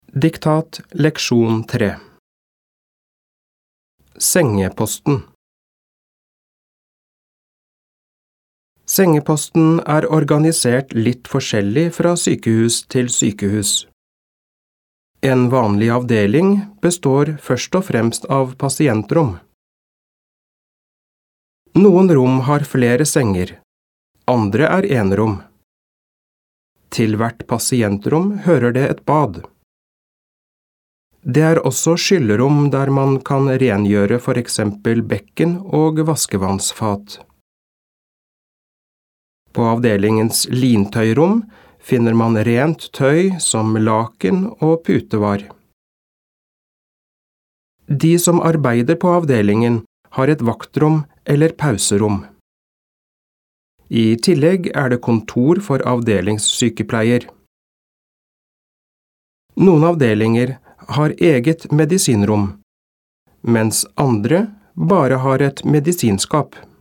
Diktat leksjon 3
• Første gang leses hele teksten, og du skal bare lytte.